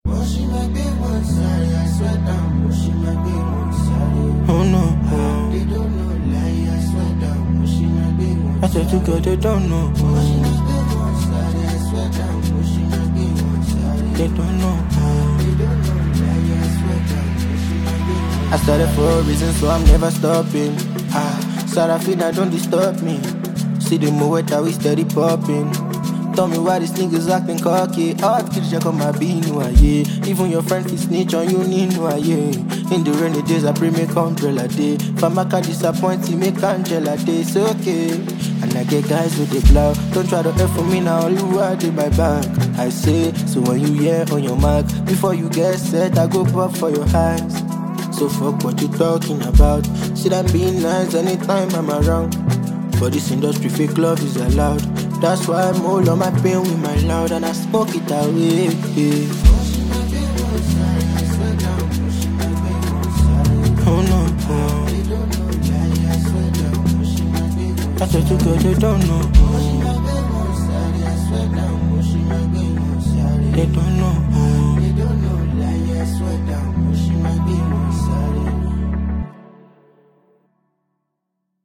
rhythmically compelling track
Afrobeats